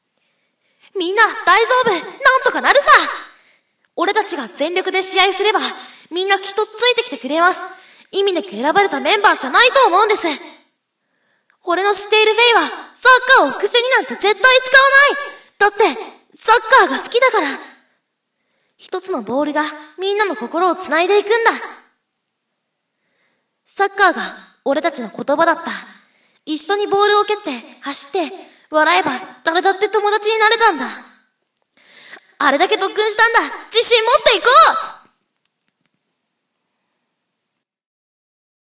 昔の声真似を振り返ろう① 松風天馬